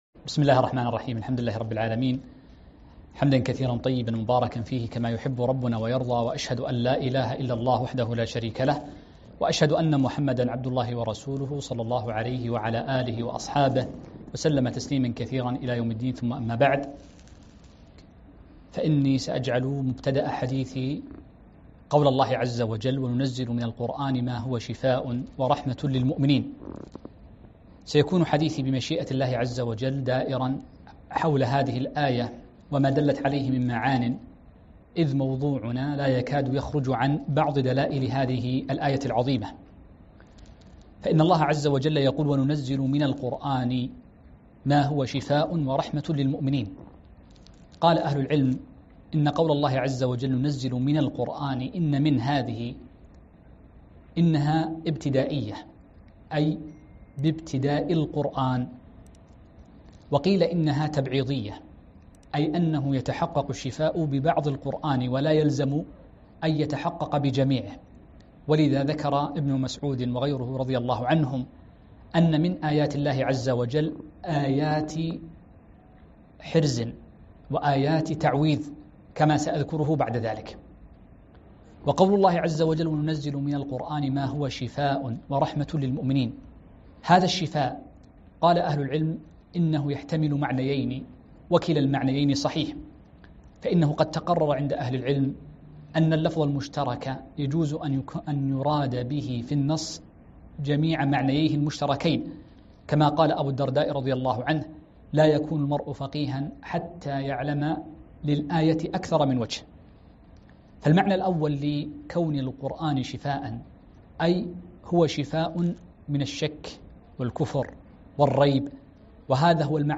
محاضرة - الرقية بالقرآن